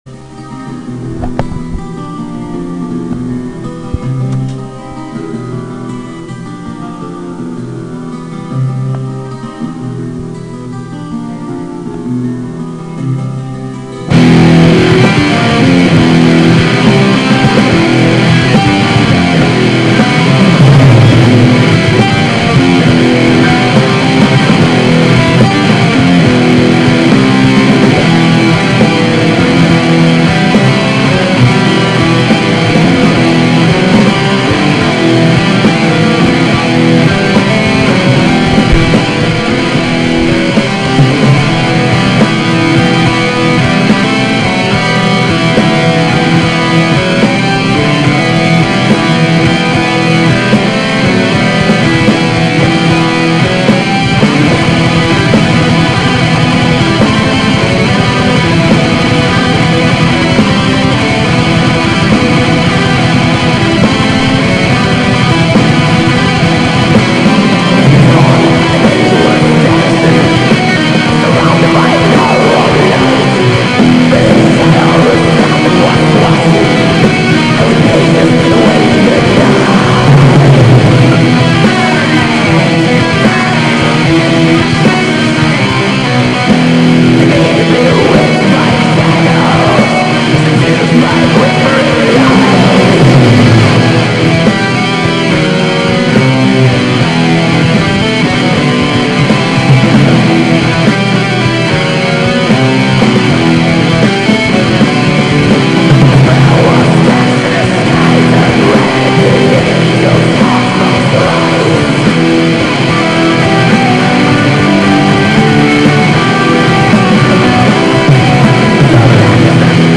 BLACK METAL MUSICK